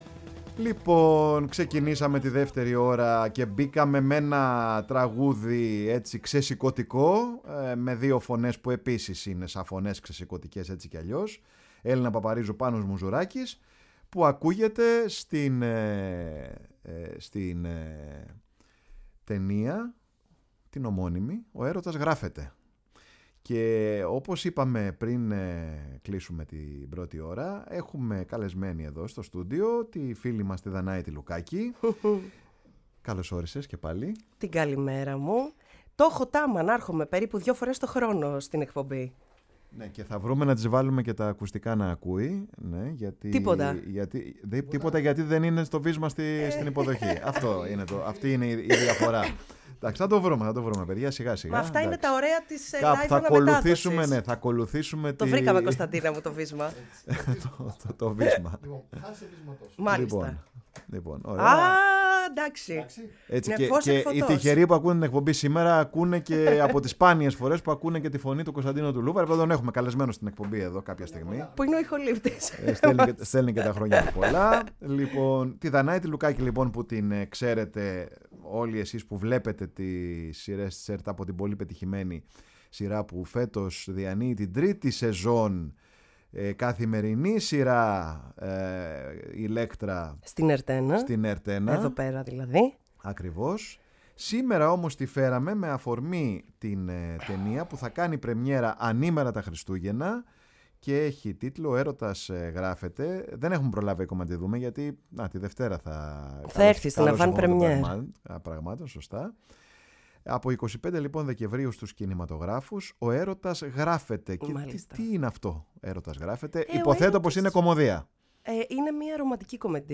Η ΦΩΝΗ ΤΗΣ ΕΛΛΑΔΑΣ Παρε τον Χρονο σου ΣΥΝΕΝΤΕΥΞΕΙΣ Συνεντεύξεις ''Ο Ερωτας γραφεται…'